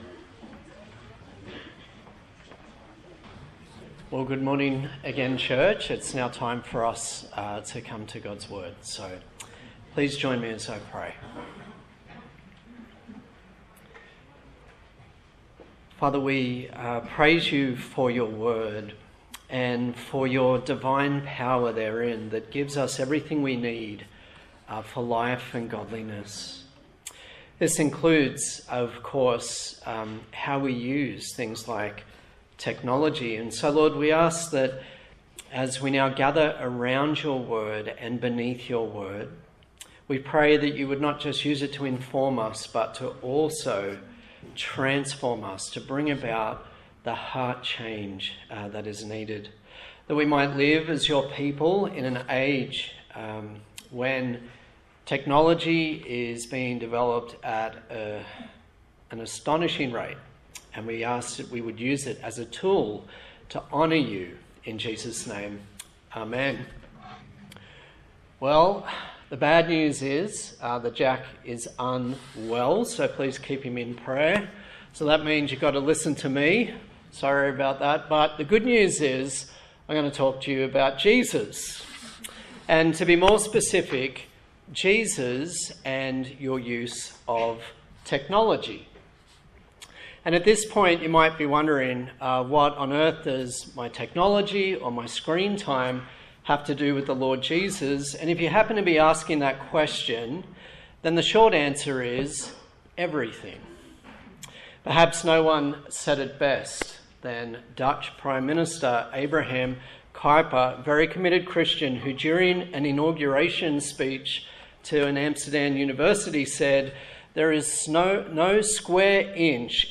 Genesis Passage: Genesis 11:1-9 Service Type: Sunday Morning